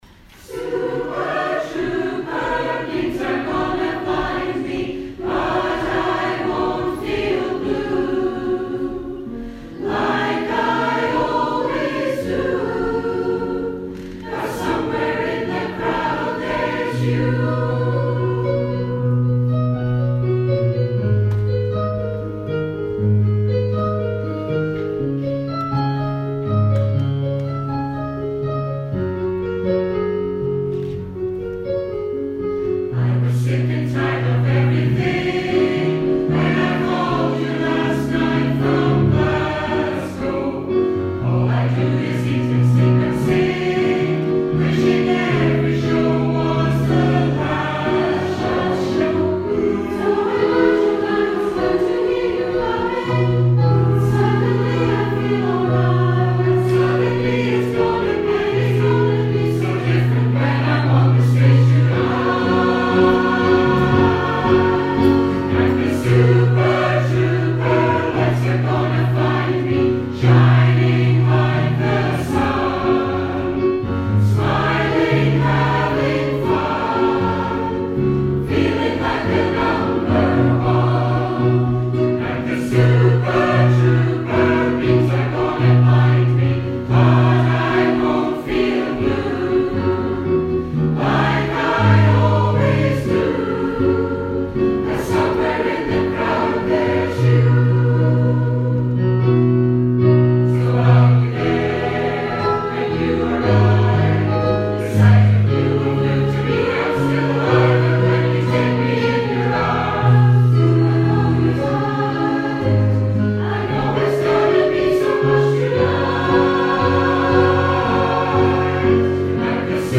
A great crowd turned out for the Guild social coffee evening with the Community Choir entertaining us with  a selection of popular songs.
Music from the shows and favourite films, with some scottish extras brought back memories for many of the audience, with a few singing along to those golden favourites.
We continued the floor tapping, singalong theme, with a medley of well known songs made famous by the group Abba.